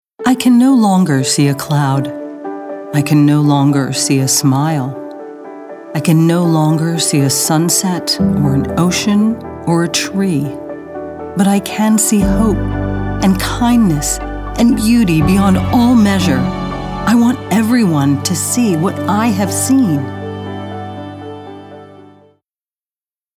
caring, concerned, confessional, conversational, genuine, informative, inspirational, middle-age, warm